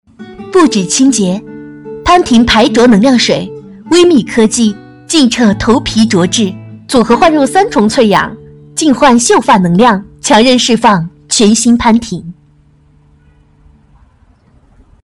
女155-洗发水广告_音频
女155-洗发水广告-音频.mp3